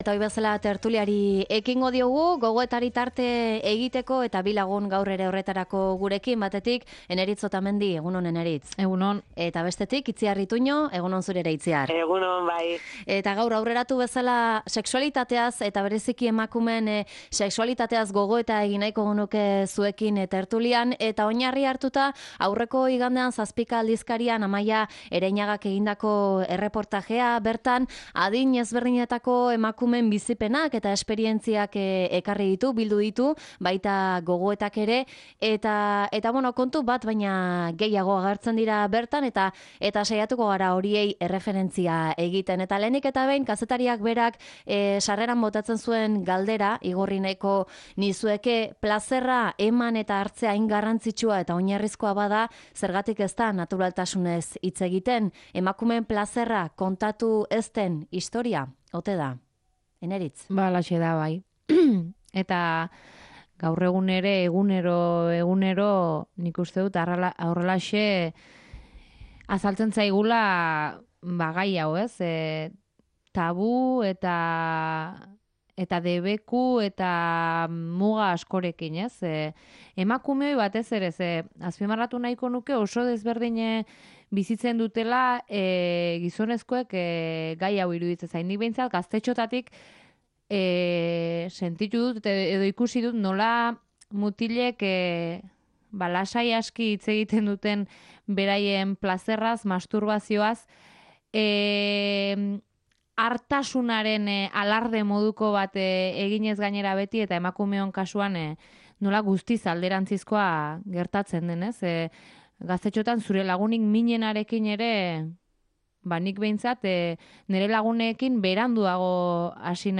Tertulia: Emakumeen plazerra kontatu ez den historia